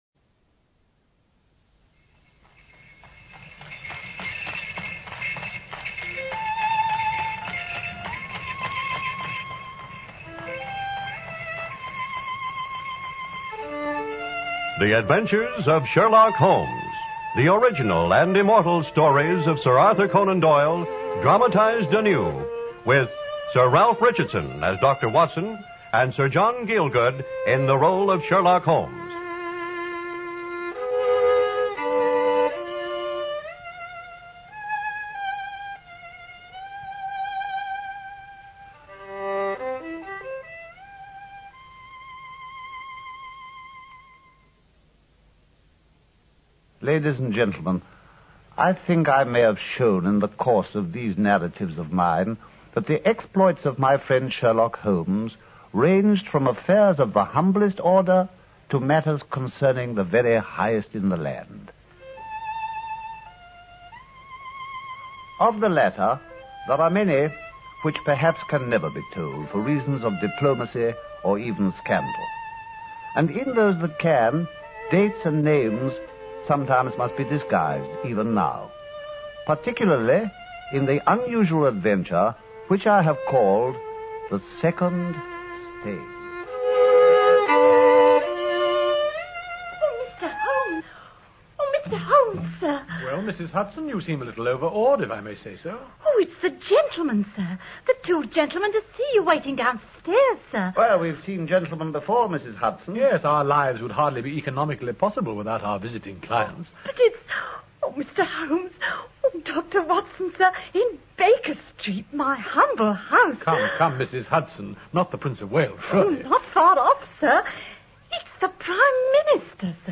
Radio Show Drama with Sherlock Holmes - The Second Stain 1954